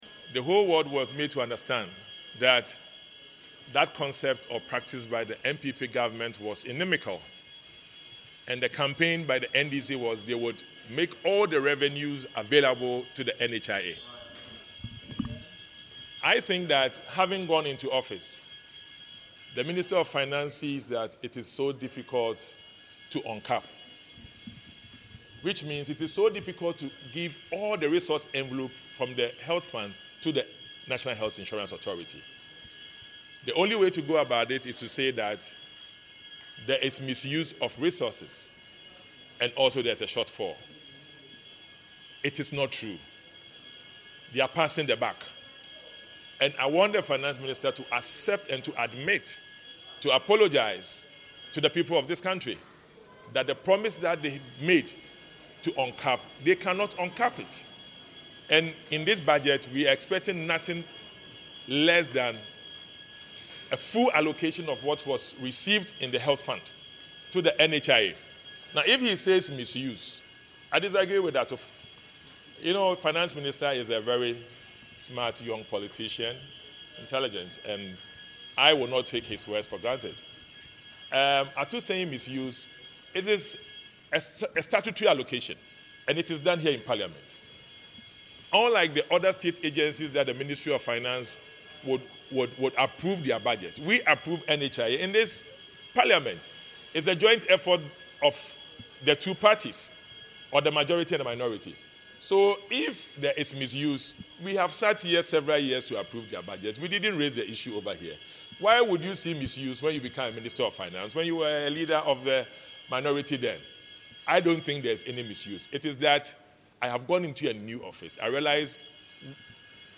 Addressing journalists on Tuesday, March 4, the Member of Parliament for Effiduase Asokore, Dr Ayew Afriye, accused the National Democratic Congress (NDC) of failing to deliver on its campaign pledge to remove the cap on NHIS funding.